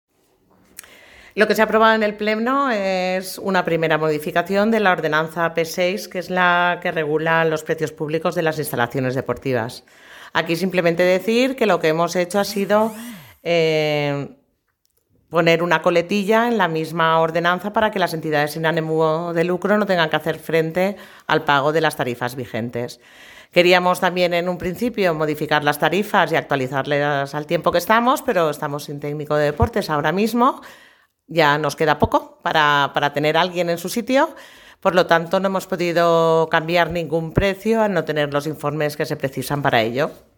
Arantxa García , regidora d’Hisenda